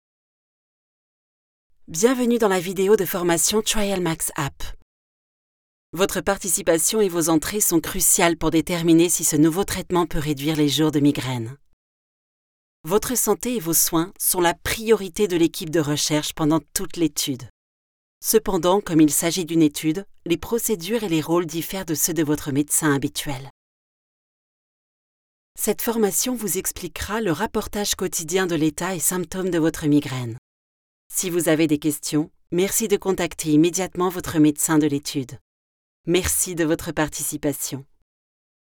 Female
Approachable, Assured, Authoritative, Bright, Bubbly, Character, Children, Confident, Conversational, Corporate, Deep, Energetic, Engaging, Friendly, Gravitas, Natural, Reassuring, Smooth, Soft, Versatile, Warm, Witty
Microphone: TLM Neumann 103